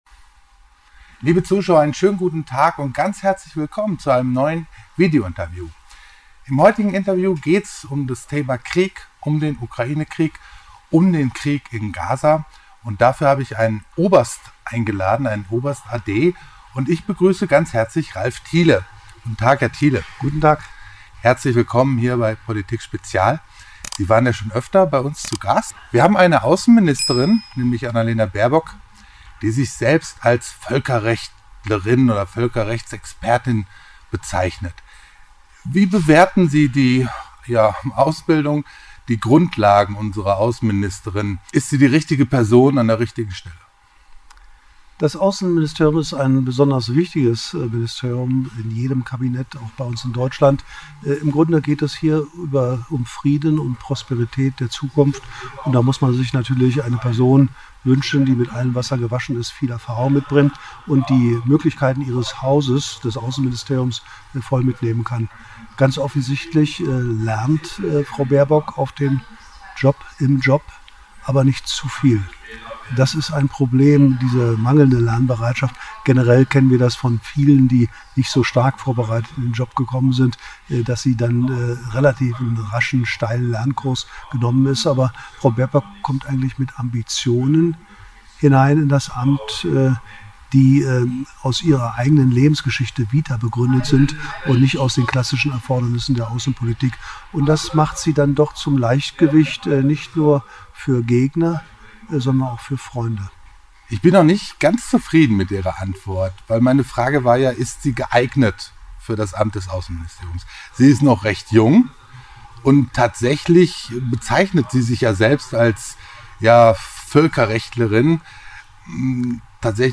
Von der Rolle der Außenministerin über Putins Position bis hin zu den wirtschaftlichen Interessen - ein schonungslos offenes Gespräch über die wahren Dimensionen des Krieges.